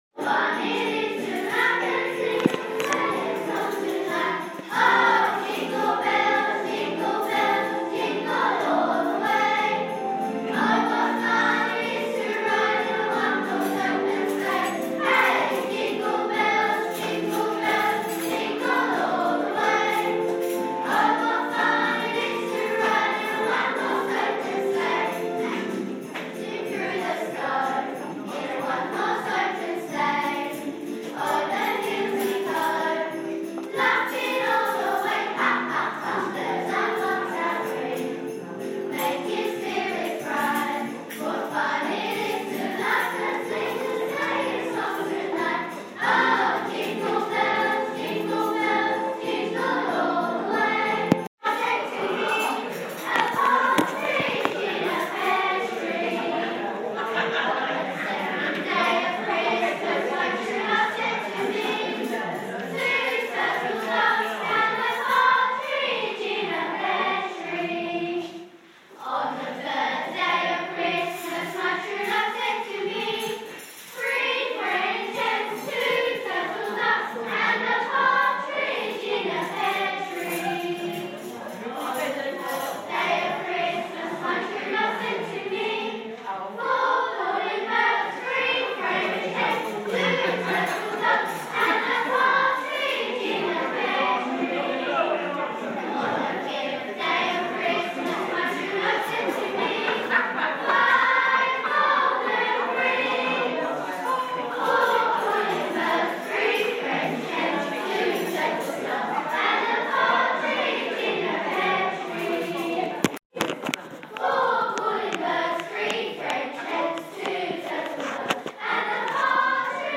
Choir - Homes in Havering